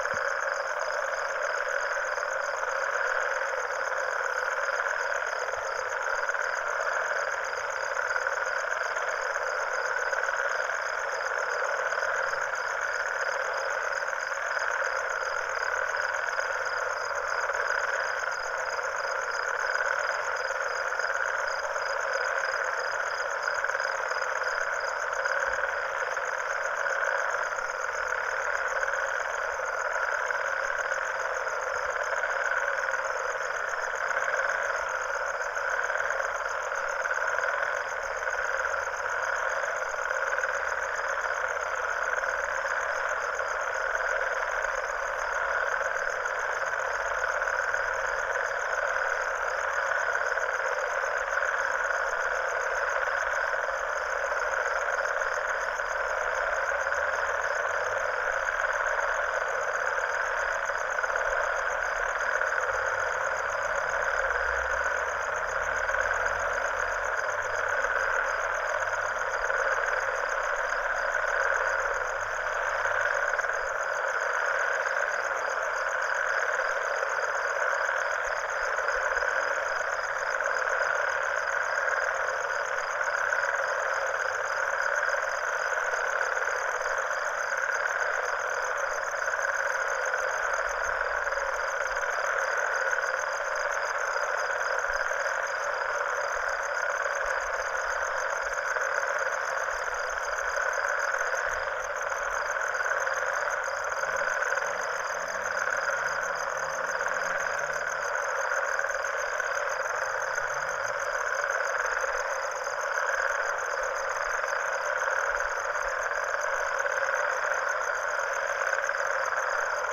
Ambiance de début d'été.